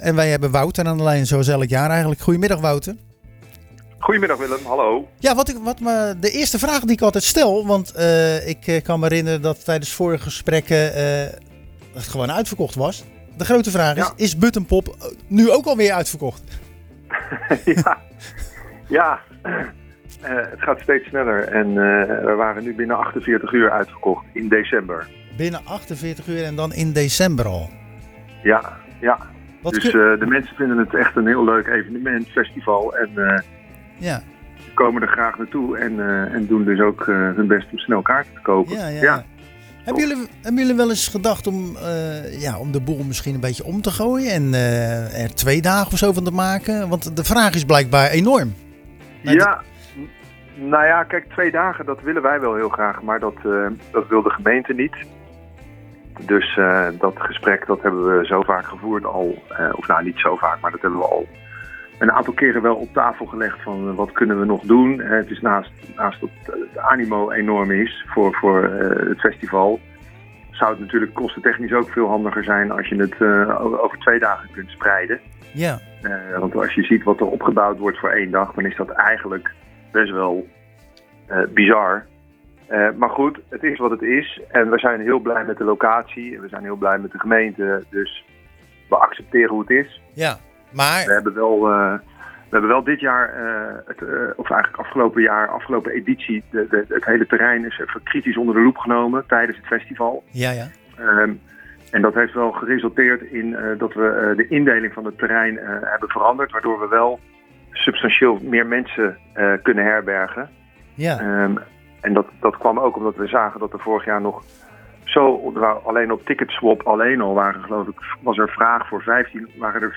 Al sinds jaar en dag bellen we tijdens de uitzending van Zwaardvis met de organisatie van Buttonpop.